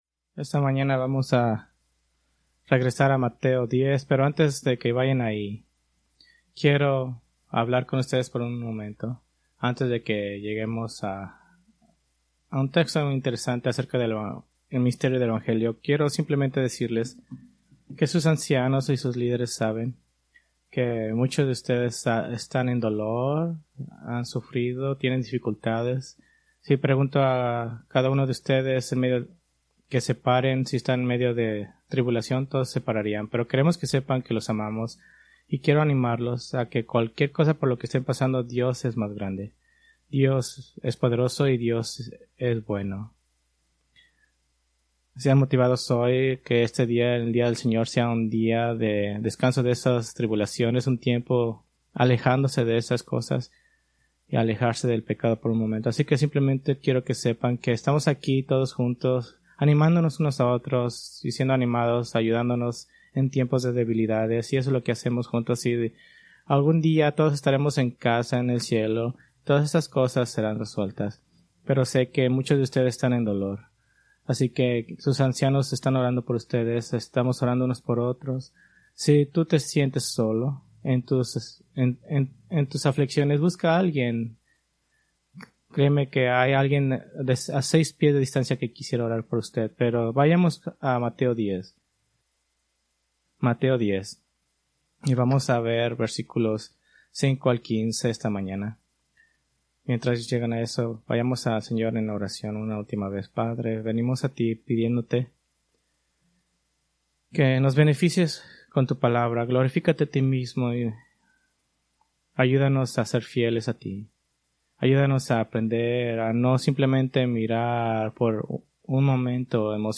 Preached April 27, 2025 from Mateo 10:5-15